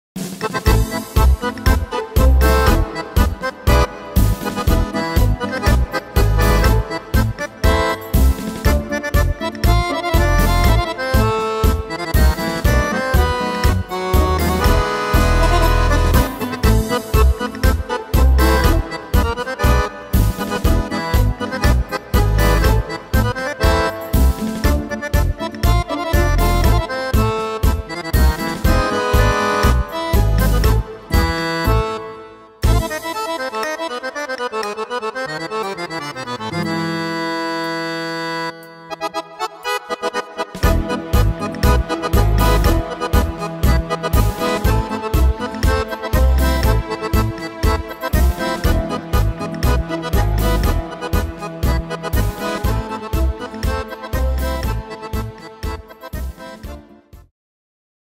Tempo: 120 / Tonart: A-moll